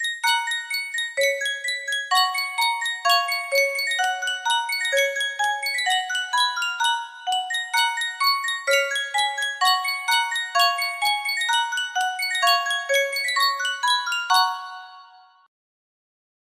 Sankyo Spieluhr - Die Vogelhochzeit YRP music box melody
Full range 60